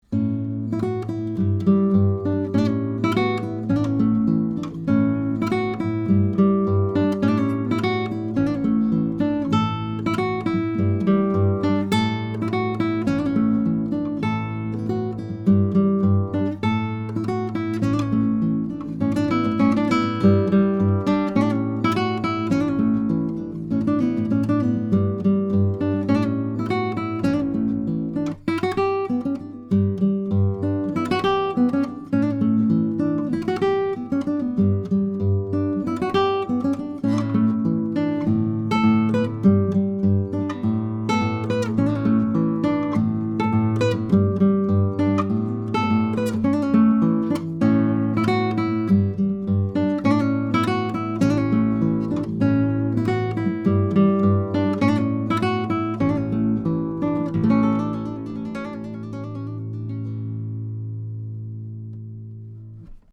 Here are a few sound files of a pair of Mini K47 on nylon 7-string guitar, going into a Trident 88 console: